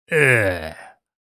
Ugh Sound Effect: Authentic Male Disgust Sound
Authentic male disgust sound effect expressing dislike for bad food, foul odors, or gross situations.
Human sounds.
Ugh-sound-effect.mp3